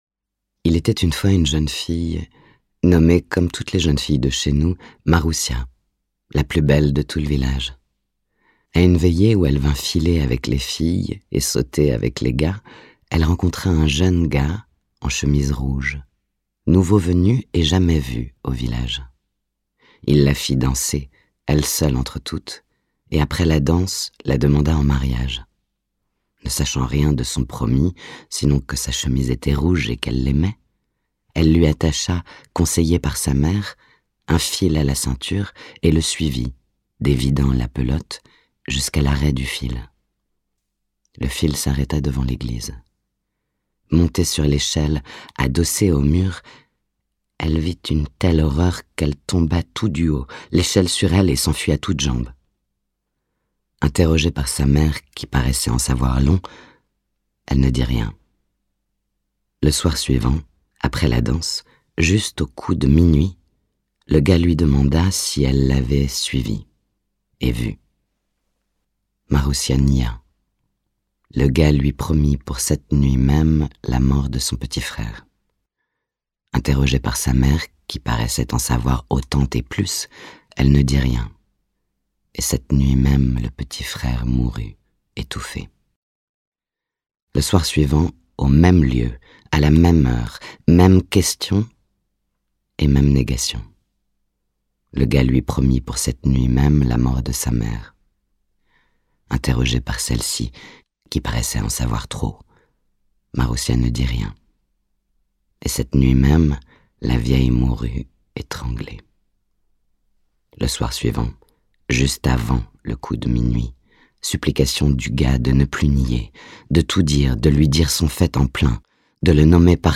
Anna Mouglalis a reçu le Prix du public en littérature classique 2020 de La Plume de Paon pour son enregistrement du livre audio Le Gars de Marina Tsvétaïeva dans la collection La Bibliothèque des voix.
le-gars-tsvetaieva-livre-audio.mp3